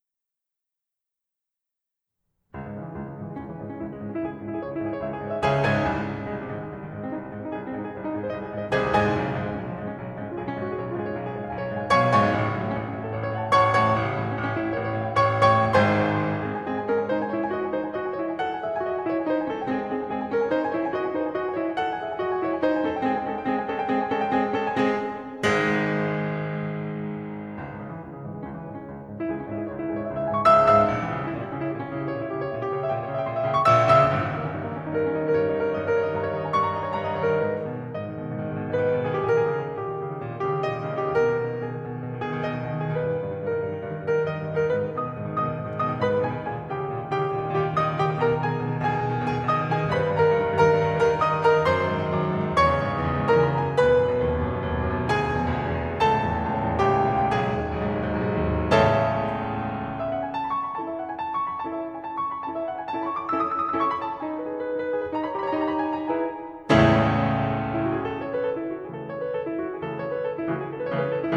The method used here, while using a very simple and well documented concealment method, it is almost impossible to hear or detect, making it a very cunning way of hiding a large amount of information covertly in an otherwise innocent audio clip.
This is the sample rate and bit depth used in this example.
Many have listened to this bit of classical music[e] and can't hear anything out of the ordinary.